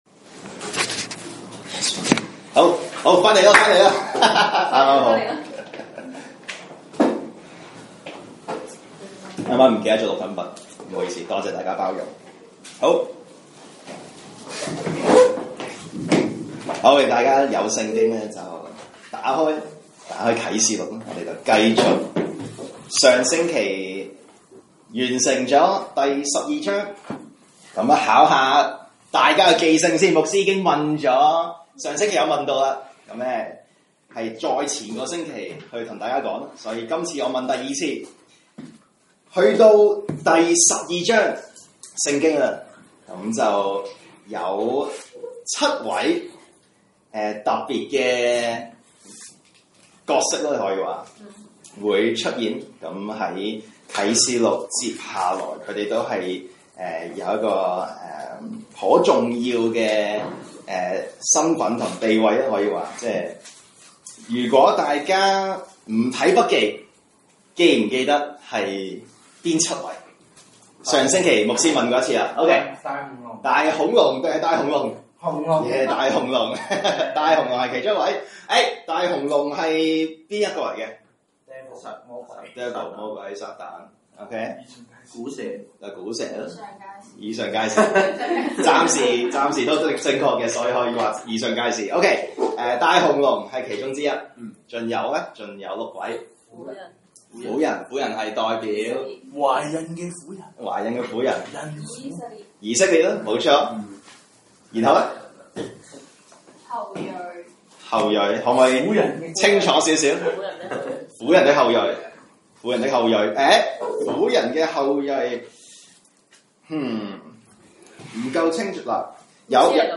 來自講道系列 "查經班：啟示錄"